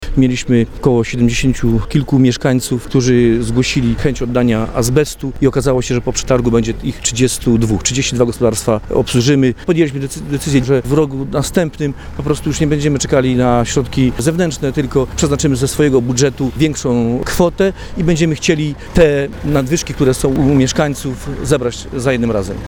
Wszystkiemu winny wzrost cen mówi wójt Kazimierz Skóra.